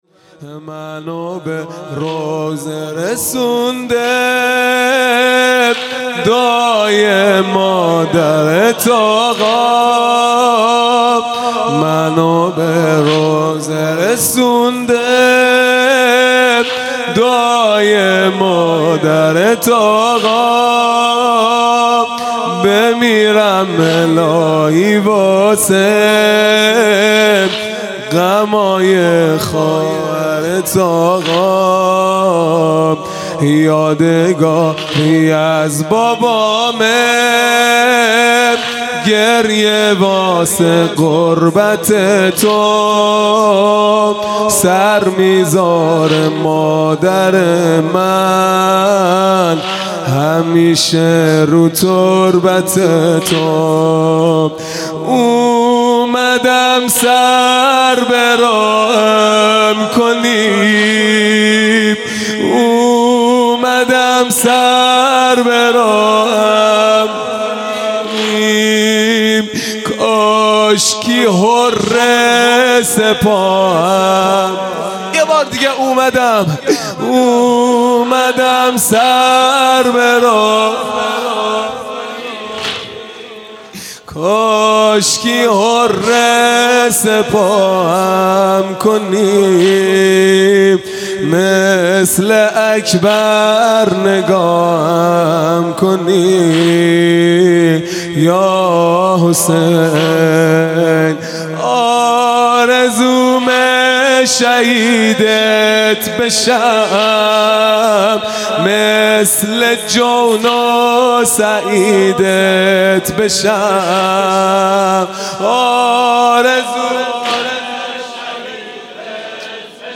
خیمه گاه - هیئت بچه های فاطمه (س) - واحد | منو به روضه رسونده
محرم 1441 | شب دوم